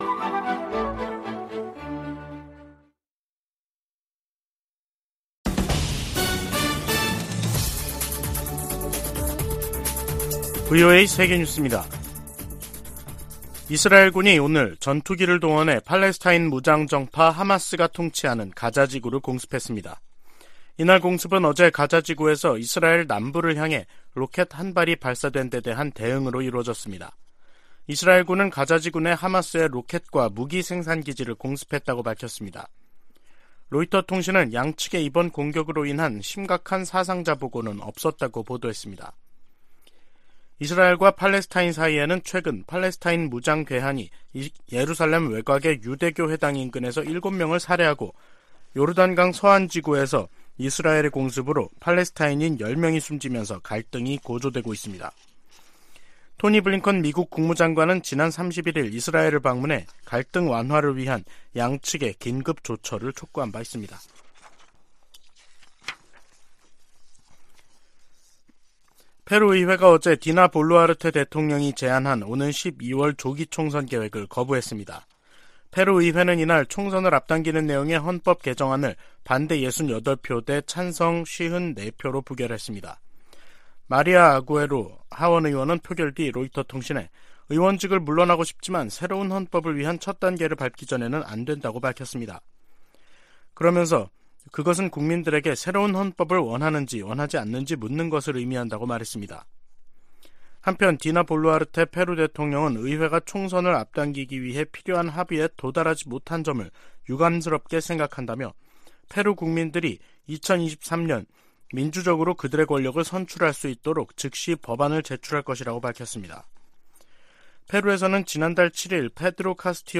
VOA 한국어 간판 뉴스 프로그램 '뉴스 투데이', 2023년 2월 2일 2부 방송입니다. 한국 국방부는 미한 공군이 1일 서해 상공에서 미 전략자산 전개 하에 2023년 첫 연합공중훈련을 실시했다고 밝혔습니다. 이에 북한은 초강력 대응을 위협했습니다. 유엔주재 미국대사가 유엔 안보리의 대북 조치에 거부권을 행사해 온 중국과 러시아를 비난하면서 북한에 대한 지속적인 압박을 예고했습니다.